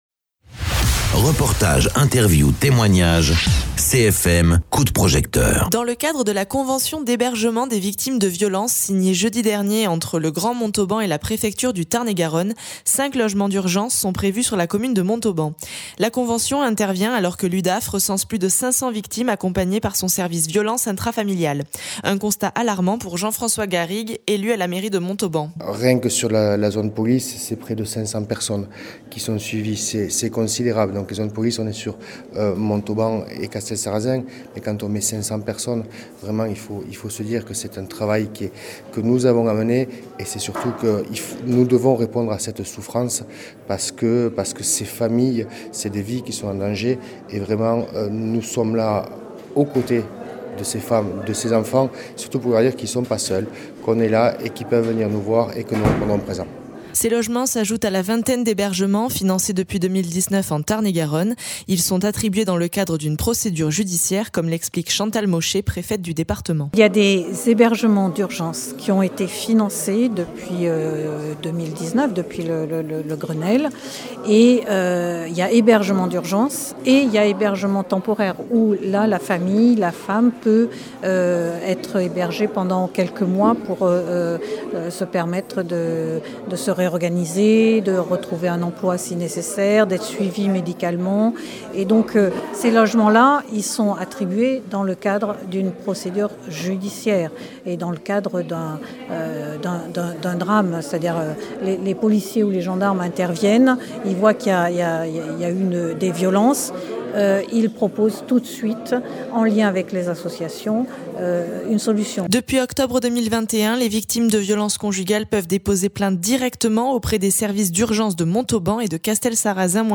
Interviews
Invité(s) : Jean-François Garrigues, conseiller municipal
Chantal Mauchet, préfète du Tarn-et-Garonne